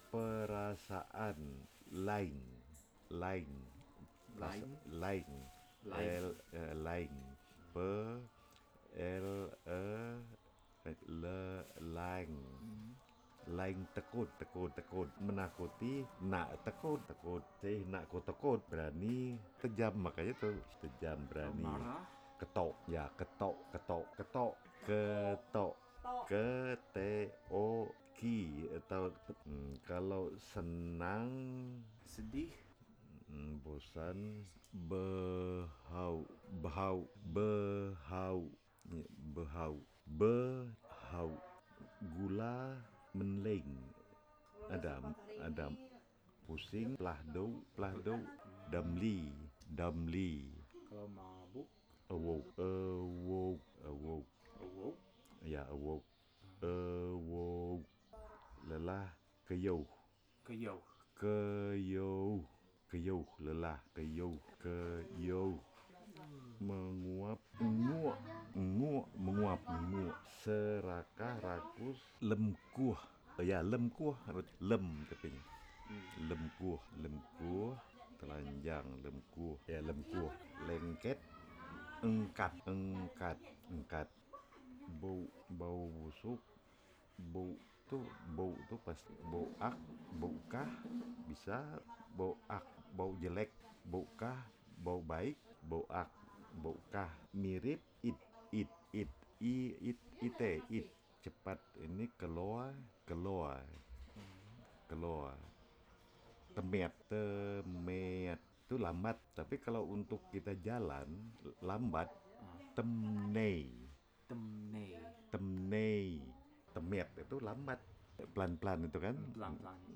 digital wav file recorded at 44.1 kHz/16 bit on a Zoom H6 recorder
East Kutai Regency, East Kalimantan, Indonesia; recording made in Samarinda, East Kalimantan, Indonesia